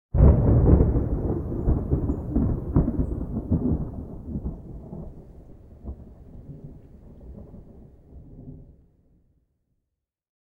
thunderfar_22.ogg